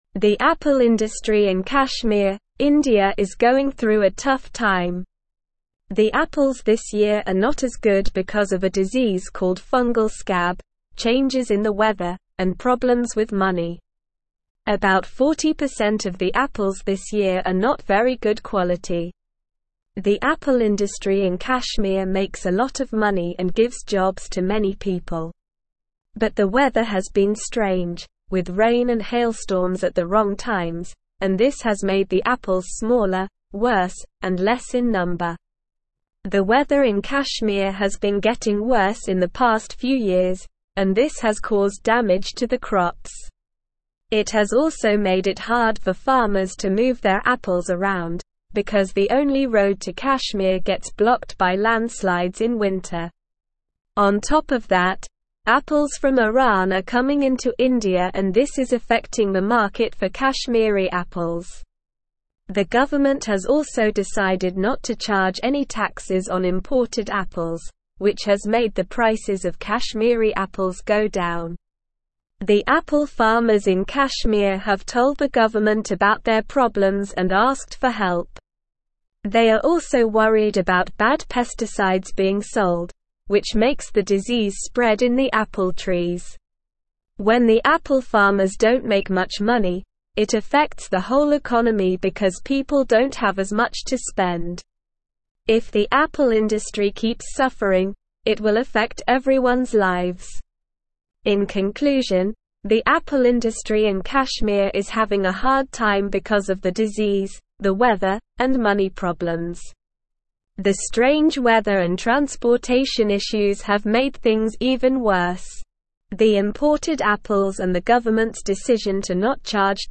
Slow
English-Newsroom-Upper-Intermediate-SLOW-Reading-Crisis-in-Kashmirs-Apple-Industry-Challenges-and-Implications.mp3